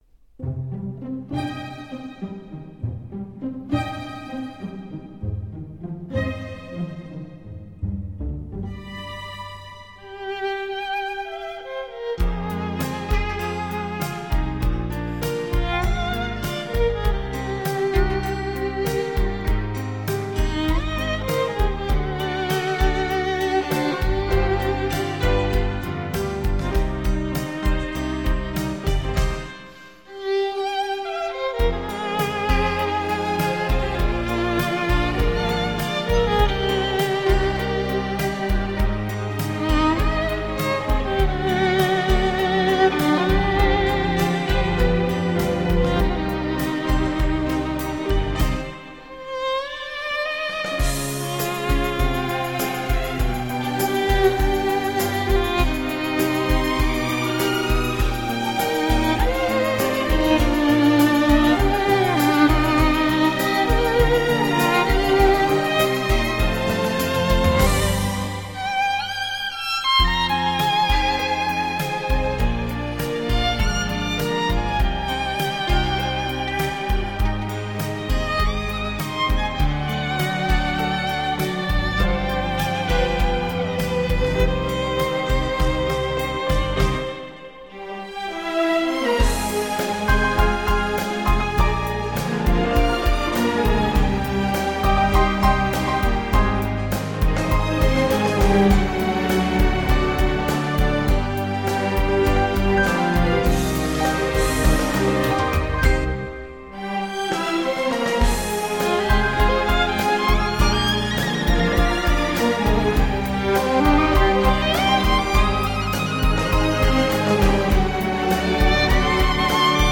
风靡全球的世界经典金曲，曲曲抒情，感人至深。